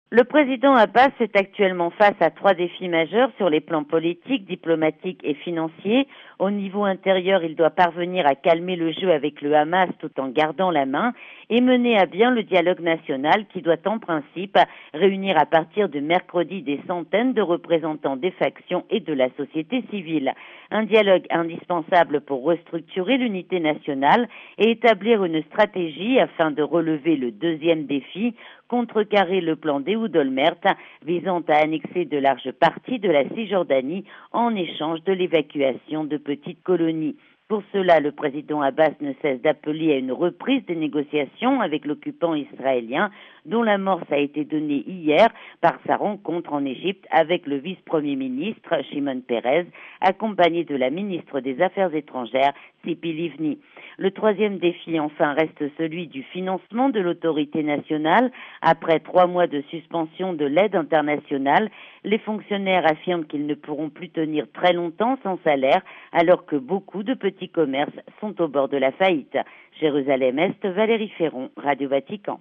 A Jérusalem